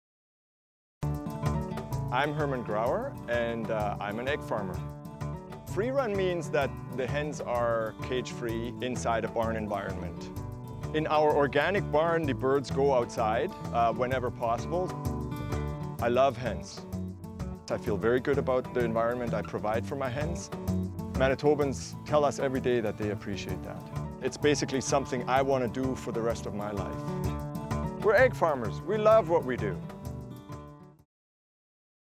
Egg farmers demonstrate their love for egg farming in these 30-second radio spots (MP3).